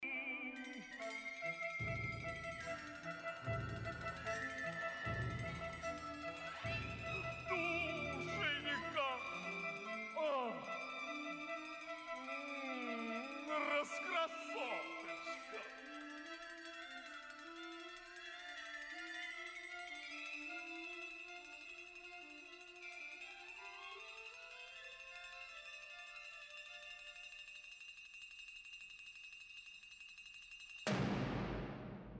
Finale - last bars of the opera with Flexatone
It is nearly impossible to play a diminuendo and a "morendo" on a Flexatone (Finale - last bars of the opera, listen to 3b), but it is the natural end of the sound of a Musical saw.